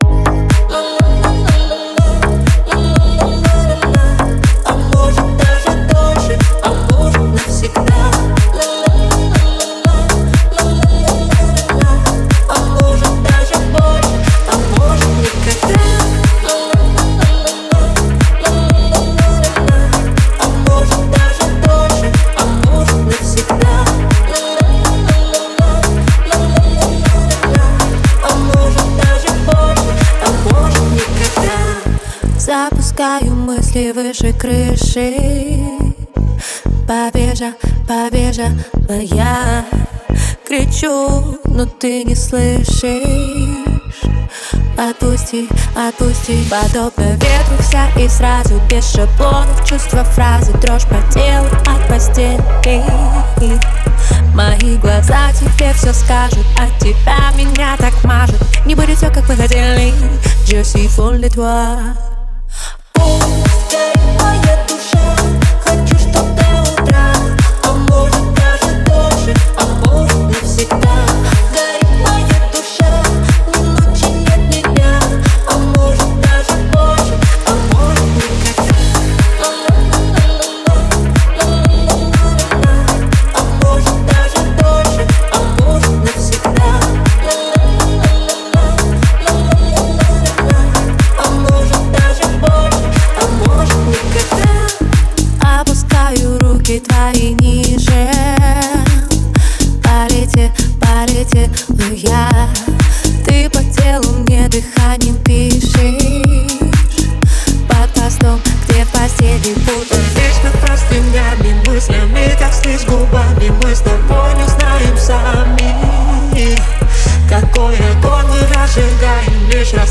это яркий и энергичный трек в жанре поп с элементами EDM
звучание становится более динамичным и танцевальным